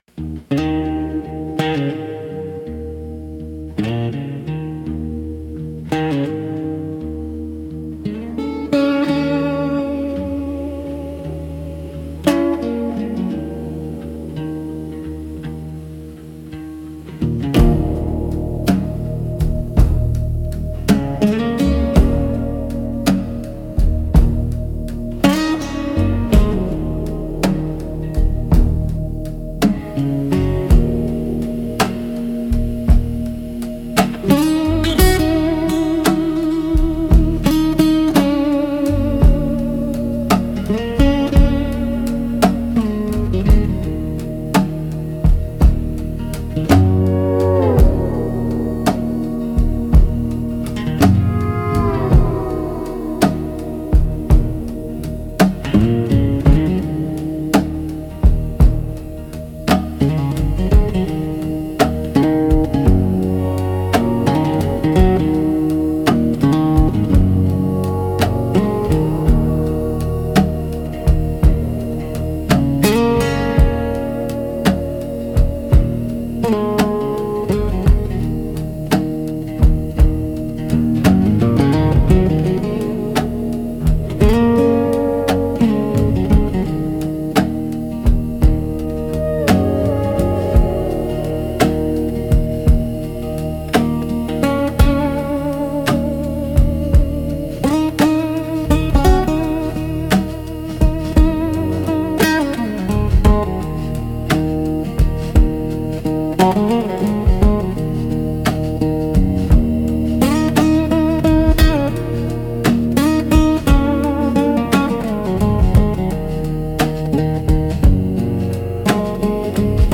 Instrumental - Graveyard Shift Guitar